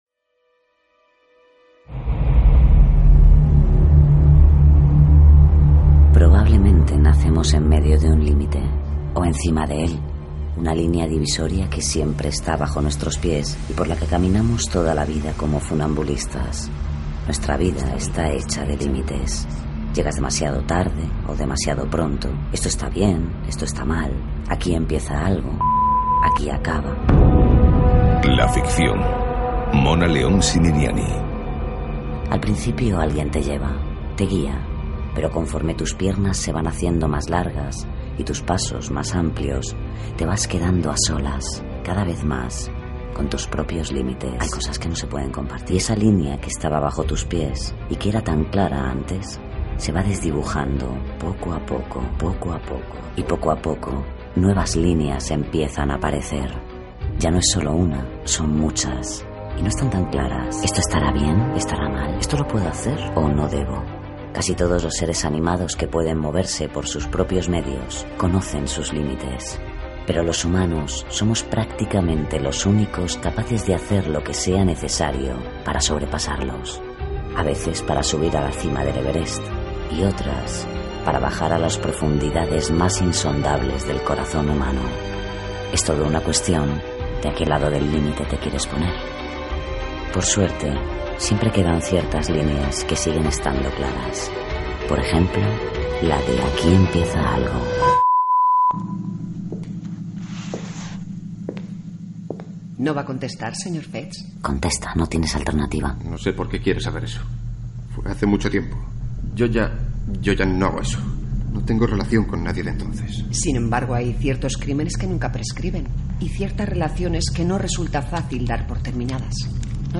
Adaptació radiofònica
Gènere radiofònic Ficció